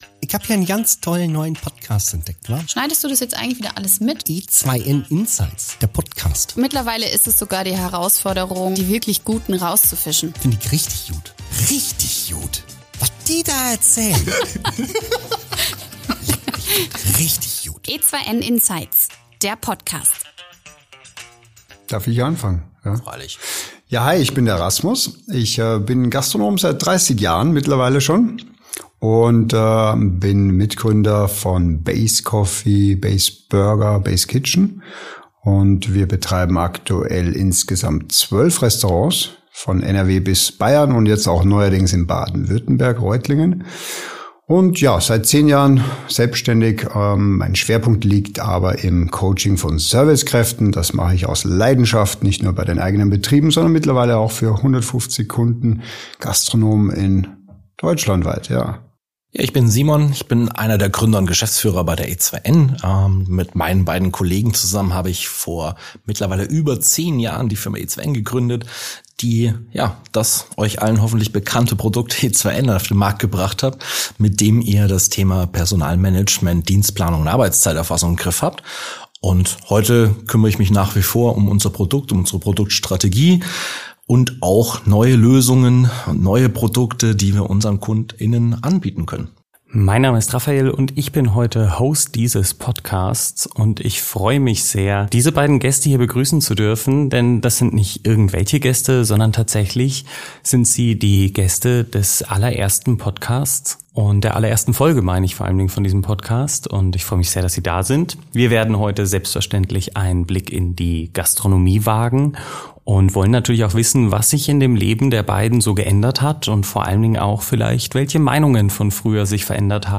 Gemeinsam sprechen sie darüber, warum Menschen der zentrale Erfolgsfaktor bleiben – gerade in digitalen Zeiten. Sie teilen wichtige Learnings, von denen Du direkt profitieren kannst: Wie teilst Du Deine Zeit als Gründer*in ein, wie sieht ein gutes Onboarding für neue Mitarbeitende aus und wie entwickelst Du Dein Team weiter?